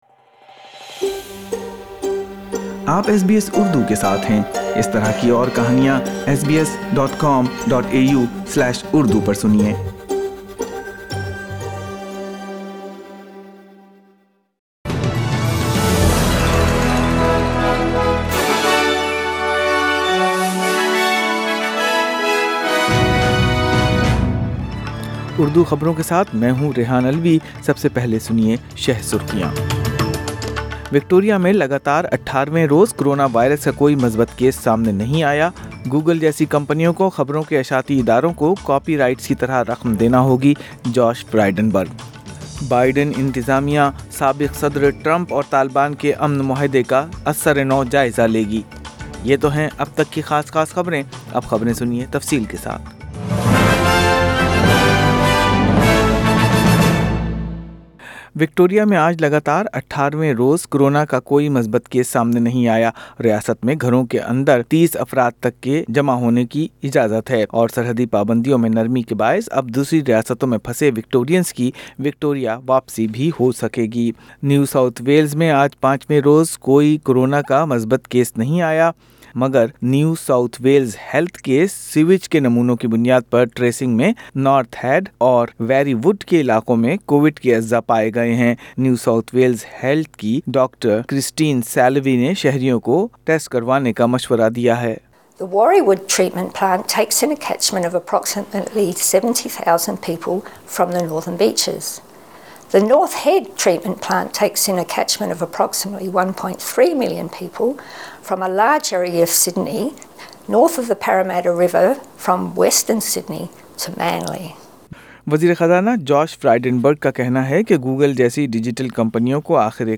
اردو خبریں اتوار24 جنوری 2021